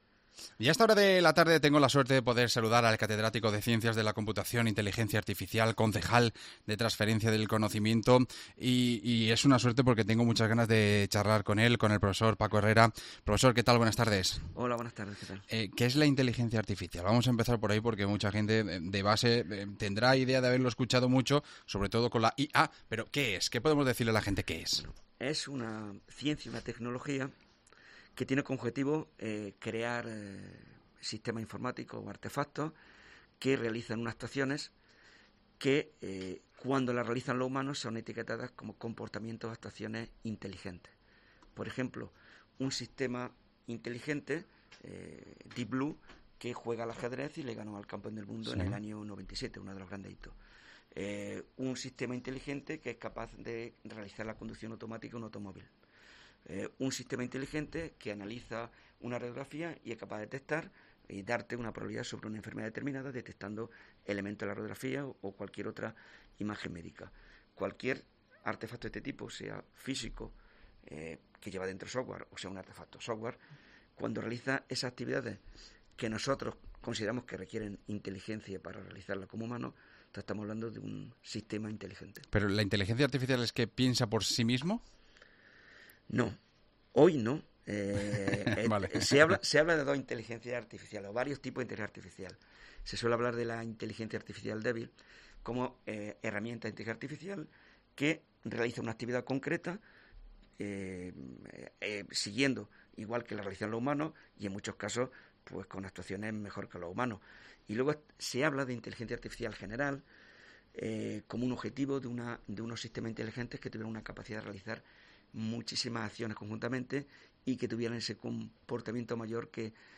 AUDIO: Conocemos todos los detalles de la IA con el catedrático de CC de la Computación e IA y concejal del Ayuntamiento de Granada